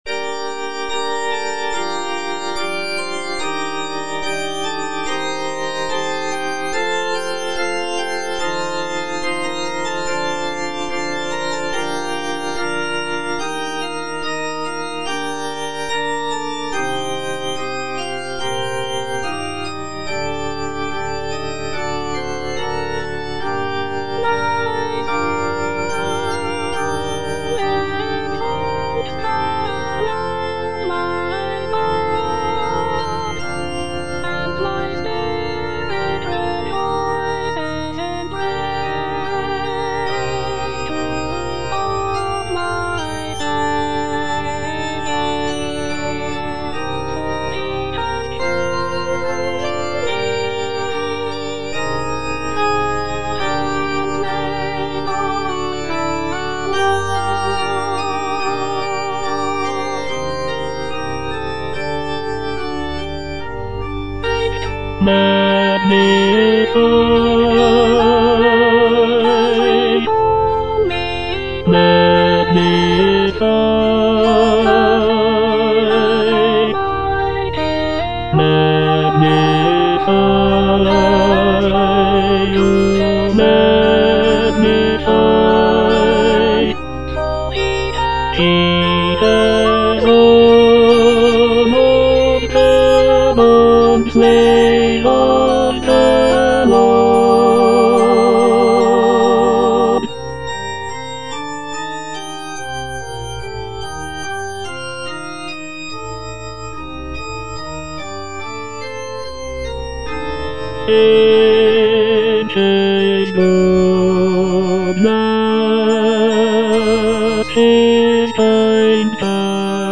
Tenor (Emphasised voice and other voices)
choral piece